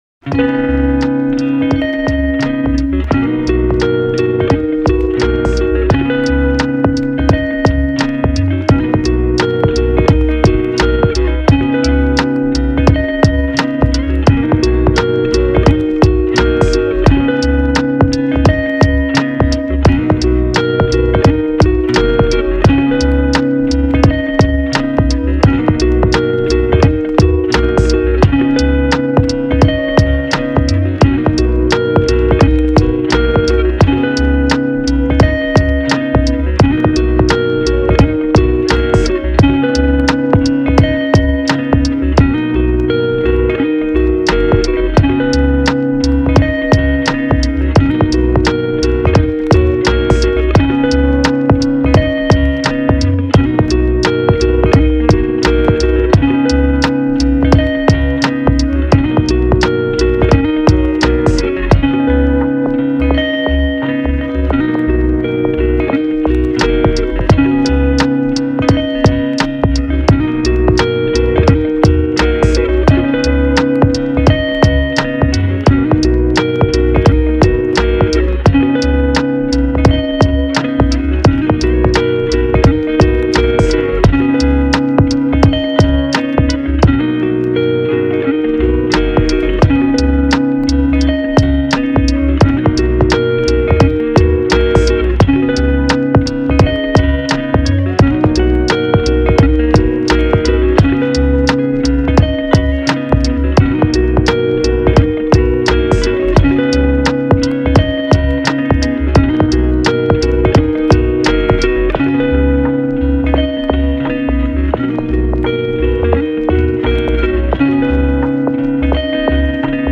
チル・穏やか
メロウ・切ない